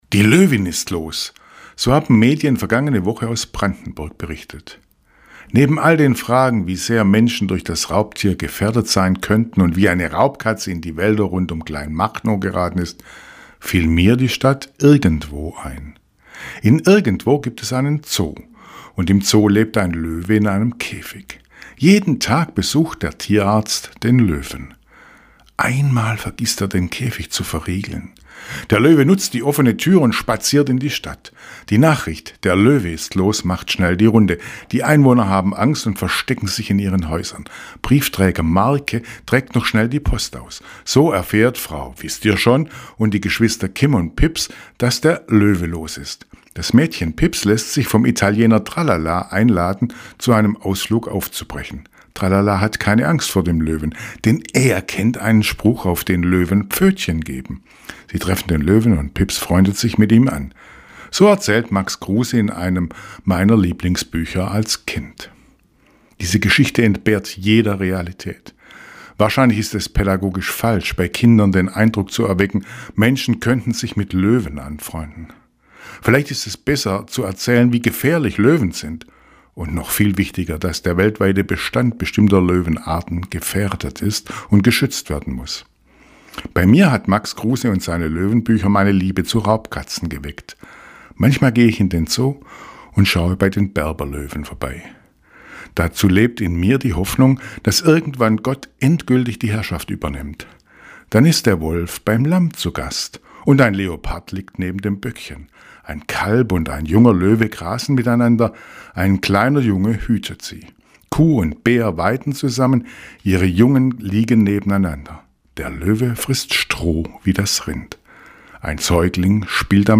Radioandacht vom 25. Juli